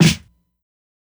SNARE_IBANG.wav